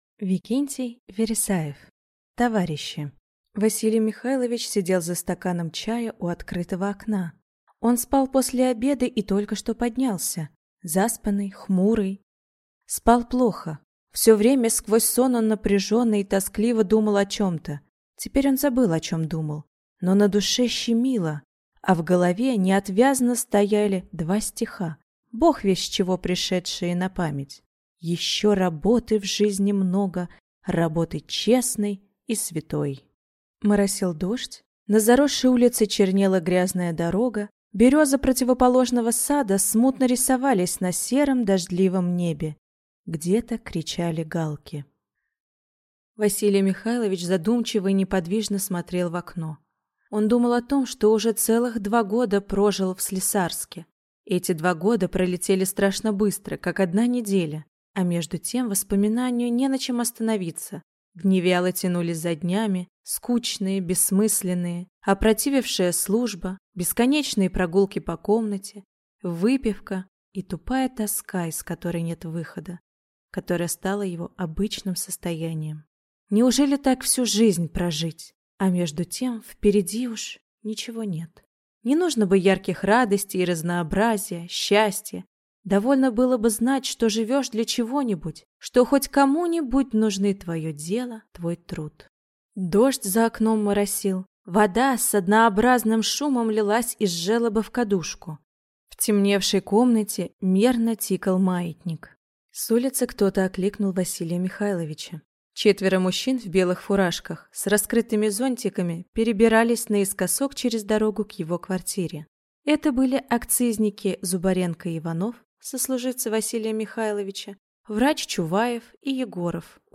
Аудиокнига Товарищи | Библиотека аудиокниг